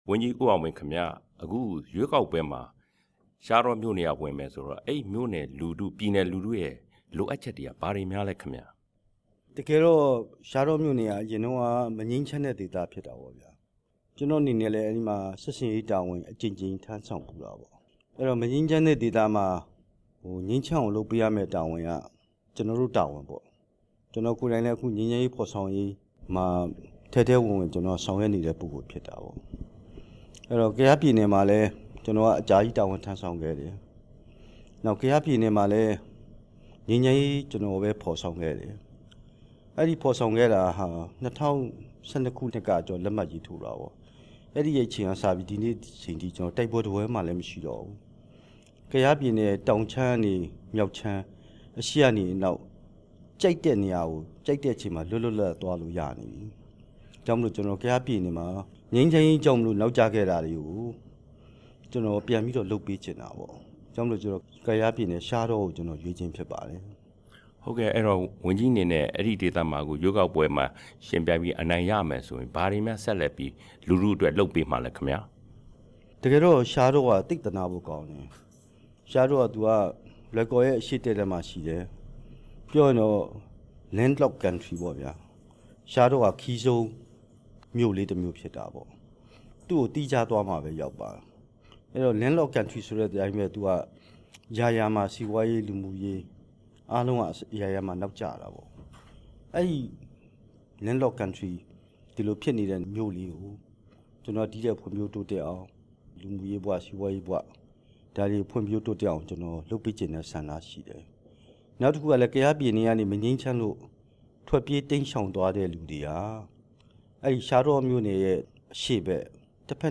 ဝန်ကြီး ဦးအောင်မင်းကို မေးမြန်းချက်